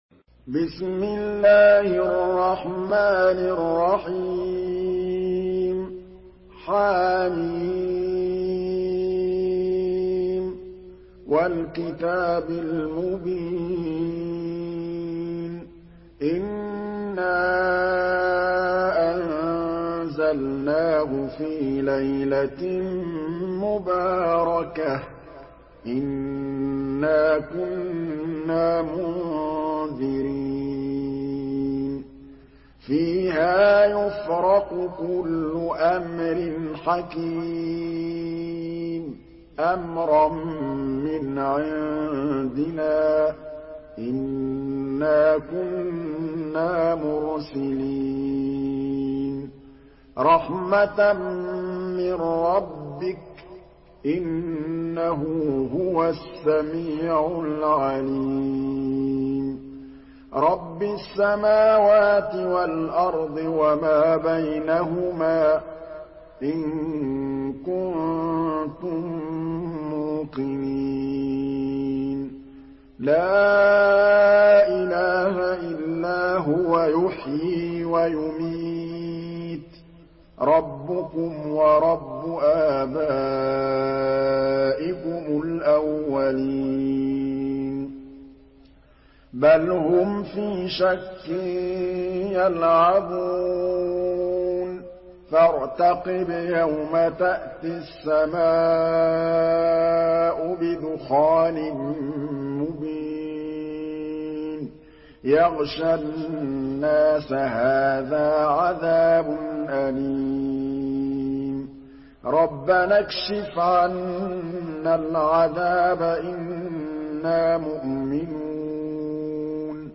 Surah Duhan MP3 in the Voice of Muhammad Mahmood Al Tablawi in Hafs Narration
Listen and download the full recitation in MP3 format via direct and fast links in multiple qualities to your mobile phone.